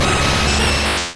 Cri de Sulfura dans Pokémon Rouge et Bleu.